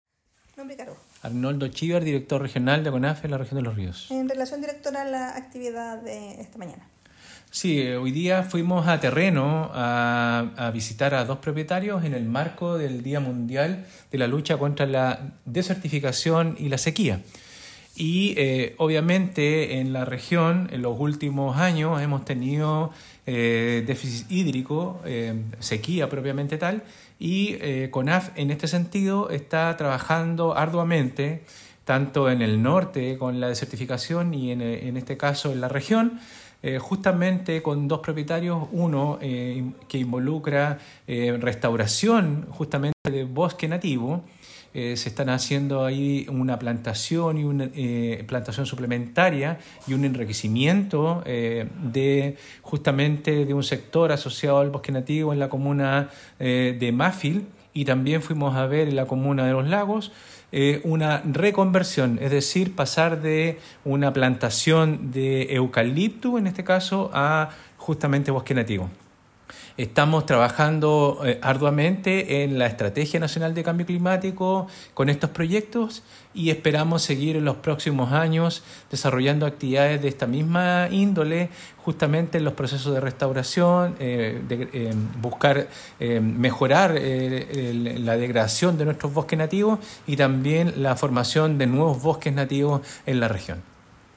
Arnoldo Shibar, director regional de CONAF Los Ríos.